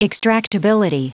Prononciation du mot extractability en anglais (fichier audio)